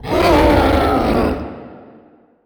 horror
Dragon Growl 4